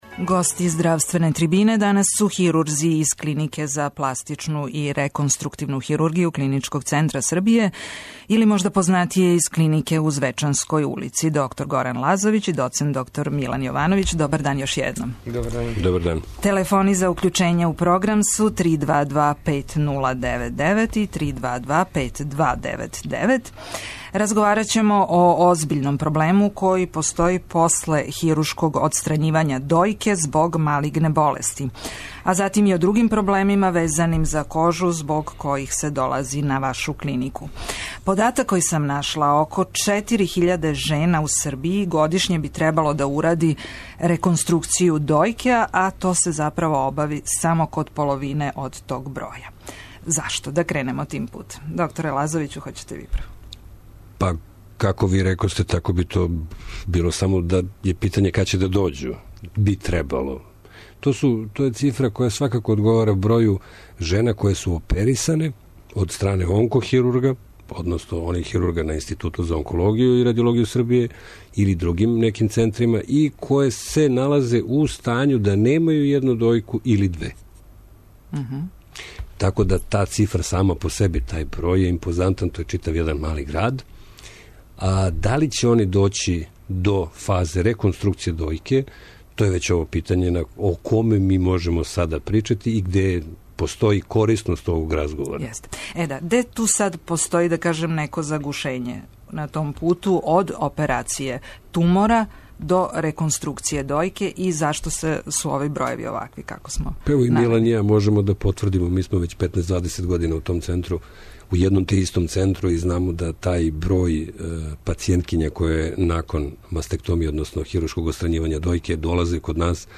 Осим о реконструкцији дојке, хирурзи у студију одговараће и на друга питања везана за интервенције на кожи, као што су уклањање младежа и осталих проблема.